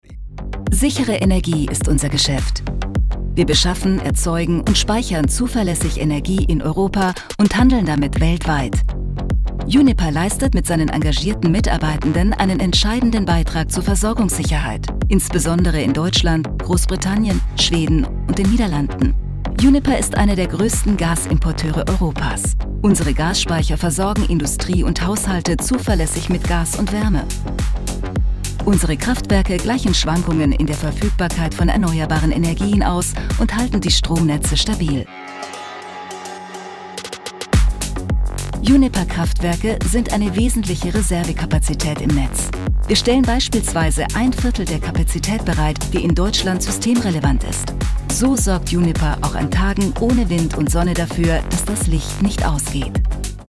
Ausgebildete Sprecherin und Schauspielerin mit variabler warmer Stimme, bekannt aus Radio- und TV.
Sprechprobe: Industrie (Muttersprache):
german female voice over talent.
Imagefilm - Uniper.mp3